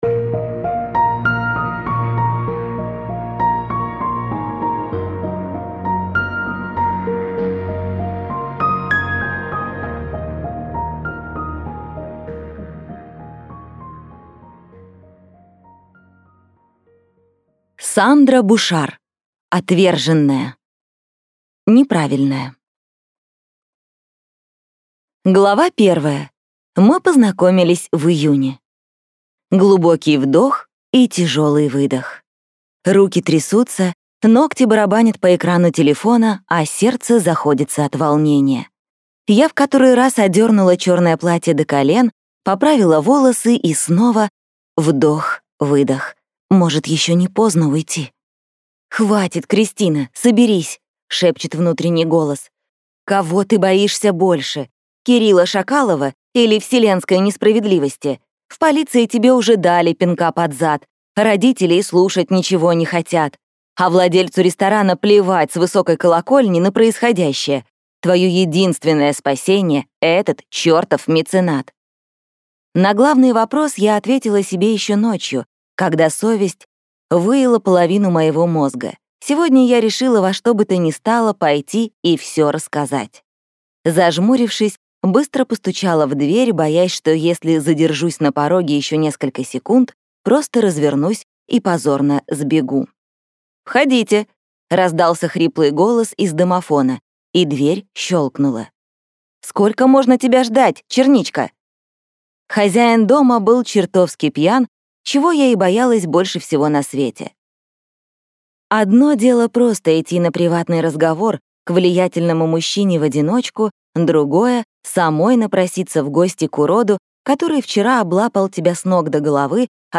Аудиокнига Отверженная | Библиотека аудиокниг